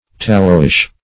Tallowish \Tal"low*ish\, a.